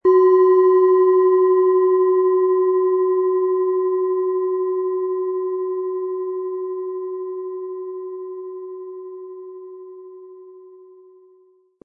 Planeten-Klangschale Wasser, in alter Tradition von Hand hergestellt.
Harmonische Töne erhalten Sie, wenn Sie die Schale mit dem kostenfrei beigelegten Klöppel ganz sanft anspielen.
SchalenformBihar
MaterialBronze